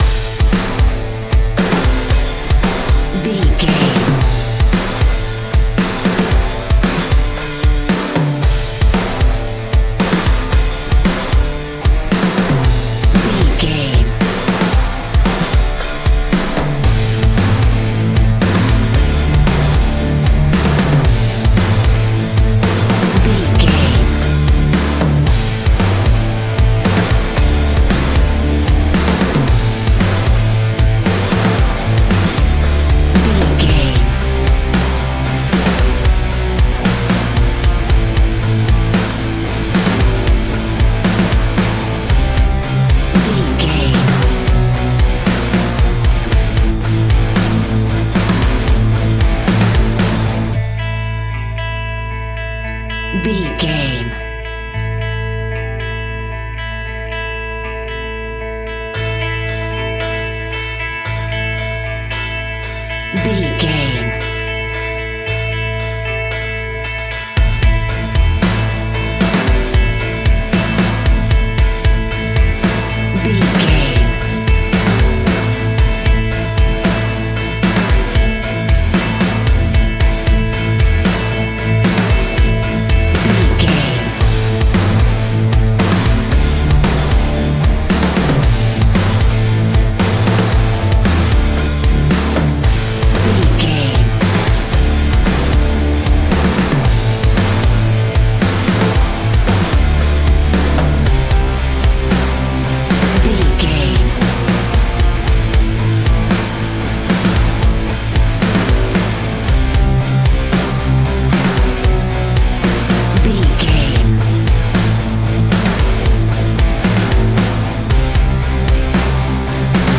Ionian/Major
A♭
hard rock
instrumentals